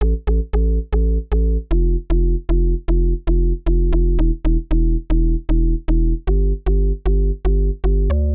MOO Bass Riff AFEGD.wav